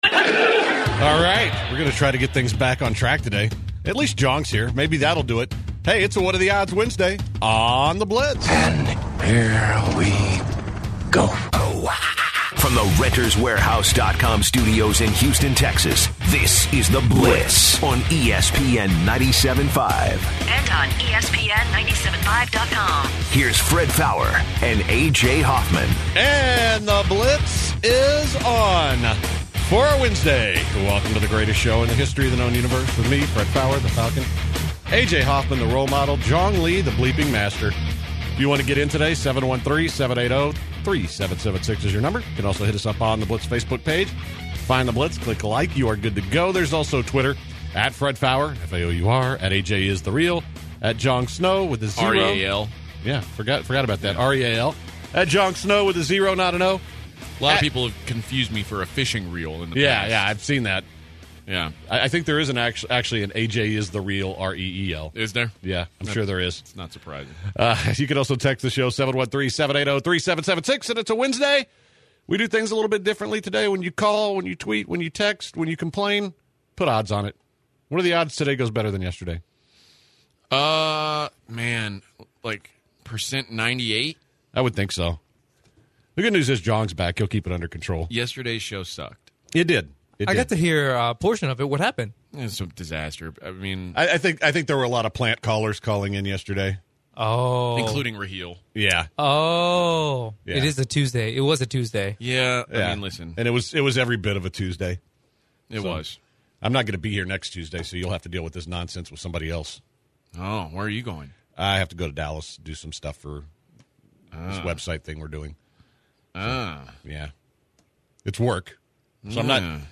It’s a What Are the Odds Wednesday on The Blitz. The first hour was filled with callers asking odds on questions.